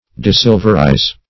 Search Result for " desilverize" : The Collaborative International Dictionary of English v.0.48: Desilverize \De*sil"ver*ize\, v. t. To deprive, or free from, silver; to remove silver from.